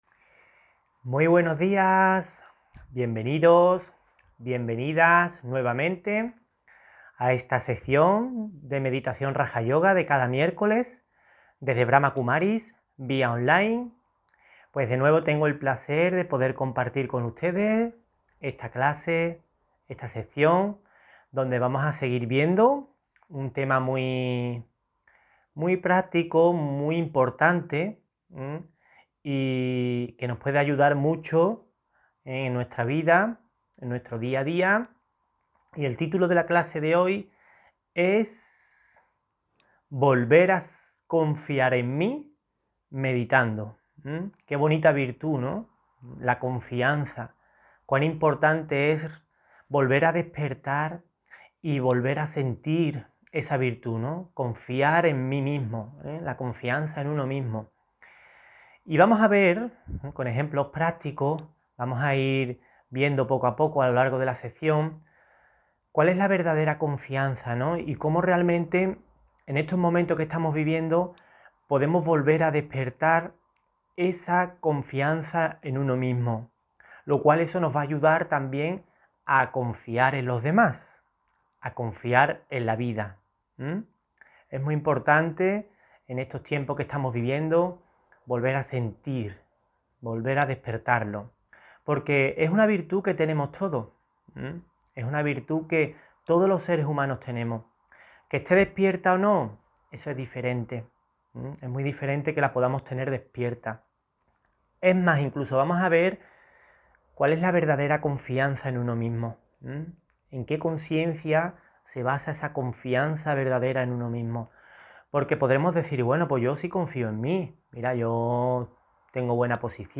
Meditación Raja Yoga y charla: Volver a confiar en mí meditando (23 Diciembre 2020) On-line desde Sevilla